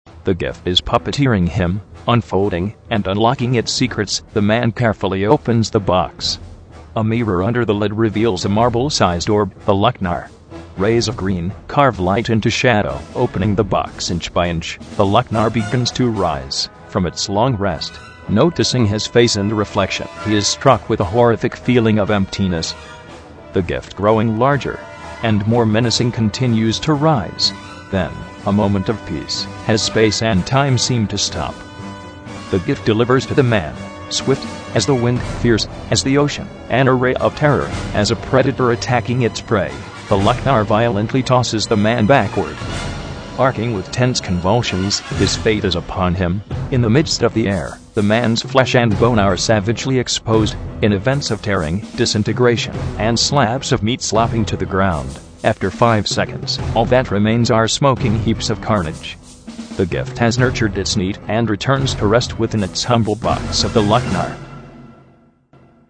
I was having too much fun testing AT&T's Natural VoicesText-to-Speech Engine... :p